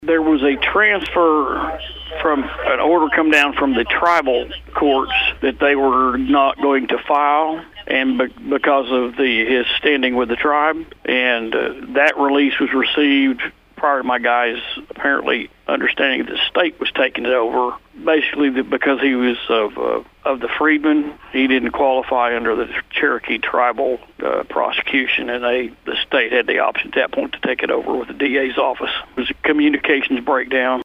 Sheriff Scott Owen explains